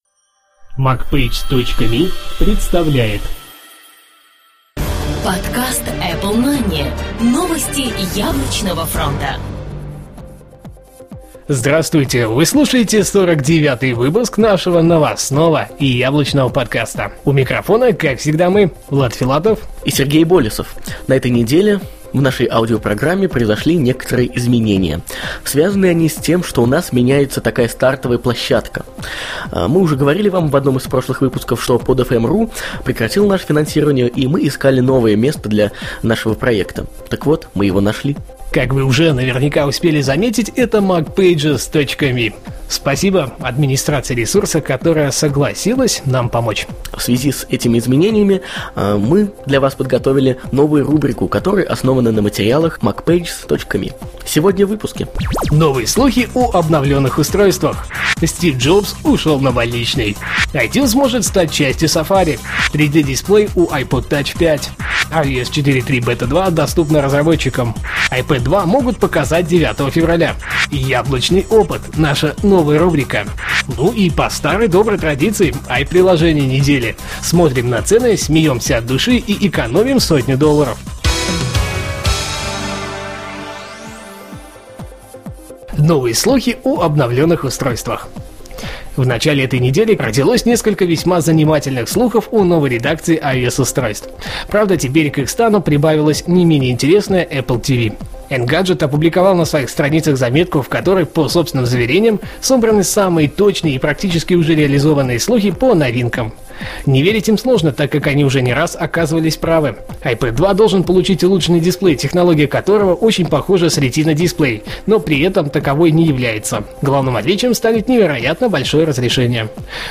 Жанр: новостной Apple-podcast